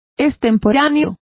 Complete with pronunciation of the translation of extemporaneous.